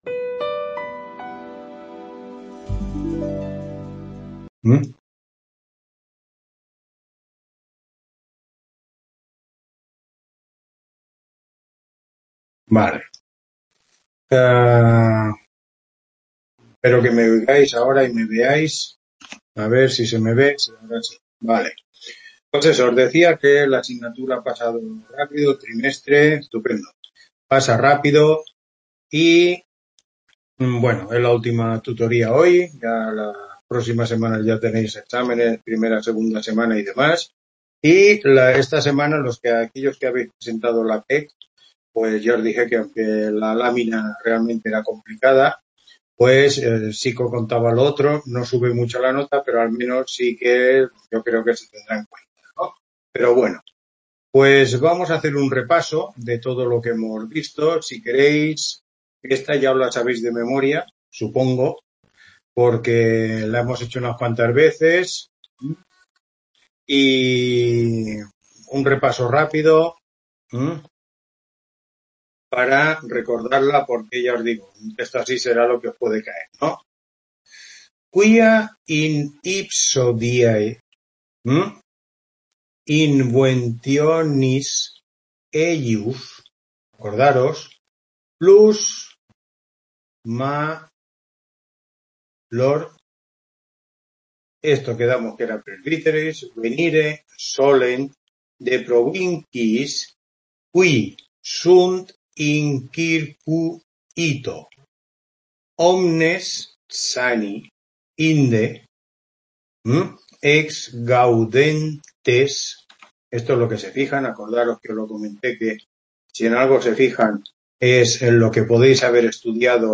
Tutoría 12